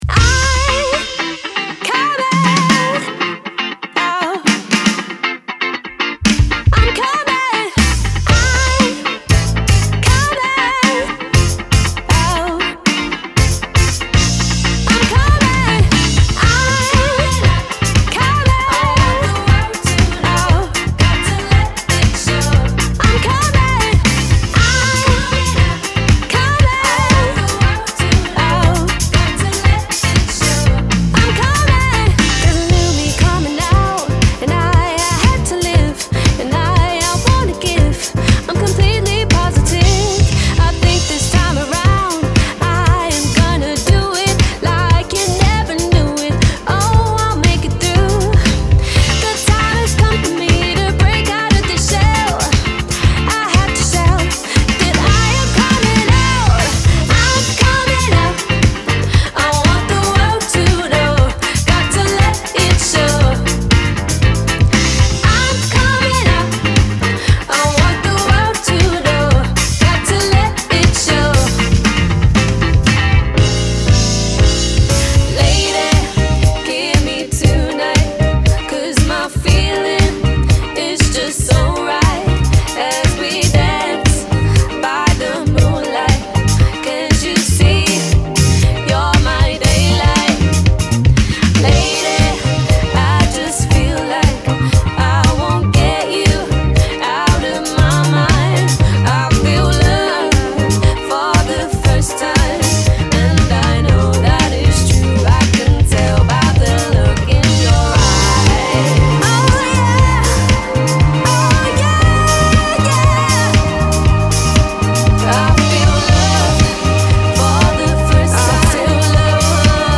• 5-piece
Female Vocals, Guitar, Bass, Keys, Drums